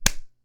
hurt.ogg